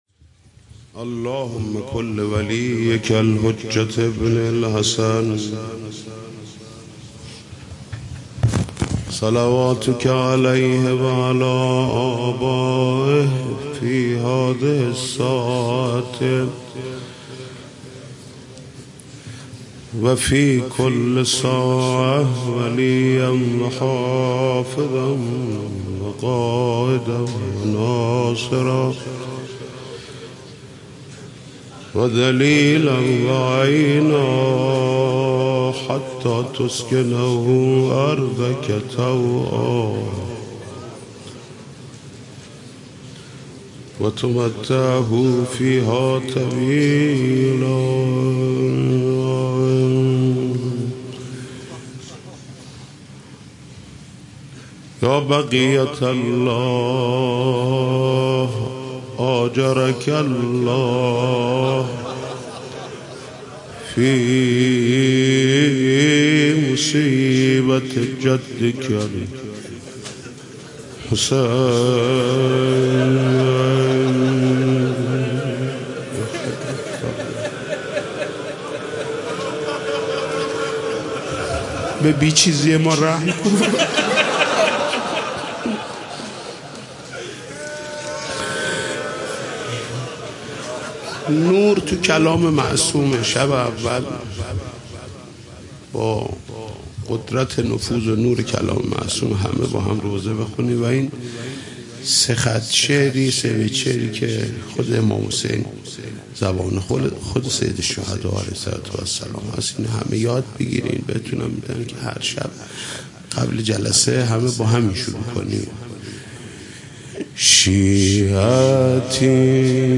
مداحی جدید حاج محمود کريمی شب اول محرم97 هيأت راية العباس